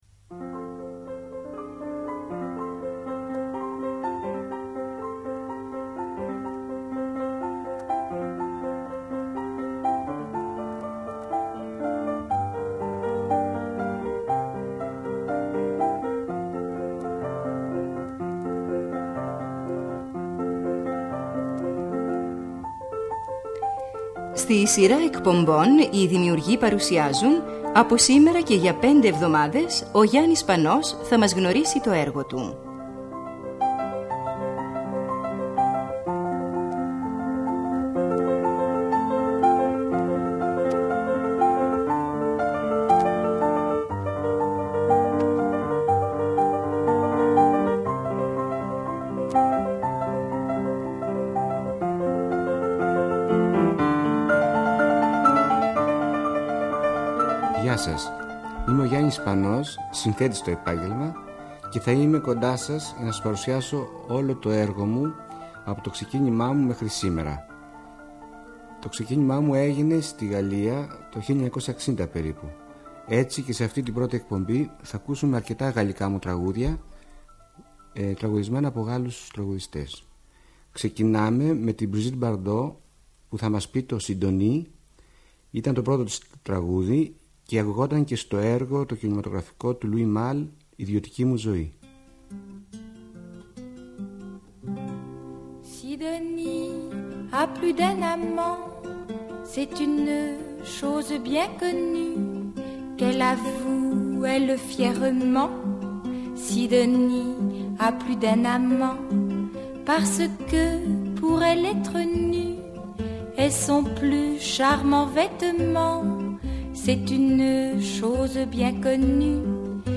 Είναι ένα από τα σπάνια ντοκουμέντα του Αρχείου της ΕΡΑ, όπου ακούμε τον Γιάννη Σπανό να αφηγείται τη ζωή του.